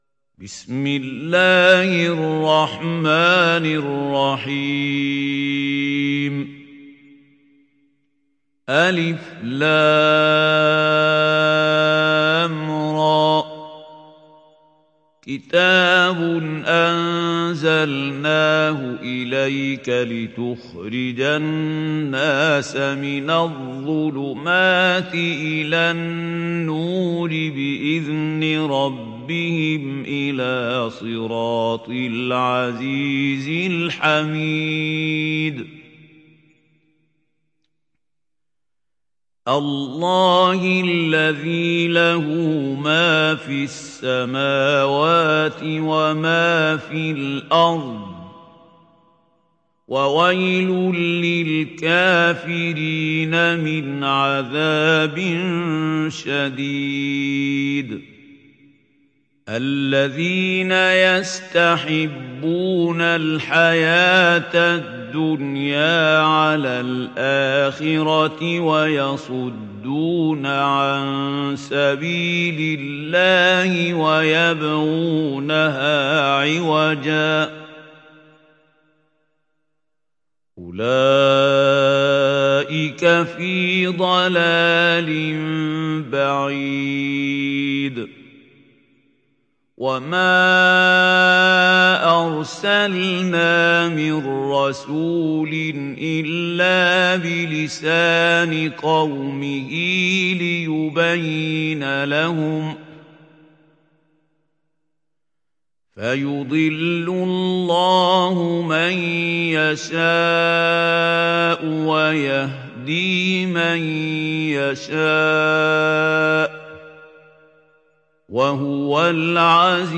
تحميل سورة إبراهيم mp3 بصوت محمود خليل الحصري برواية حفص عن عاصم, تحميل استماع القرآن الكريم على الجوال mp3 كاملا بروابط مباشرة وسريعة